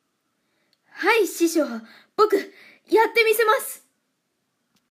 サンプルボイス 素直、真面目【少年】